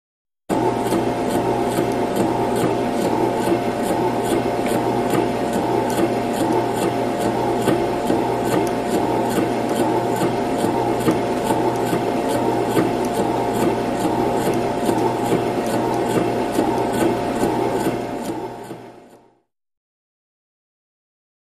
ConveyerBeltMotor PE276301
Conveyer Belt 3; Conveyor Belt; Repetitive Movement Over Motor / Fan Drone, Close Perspective.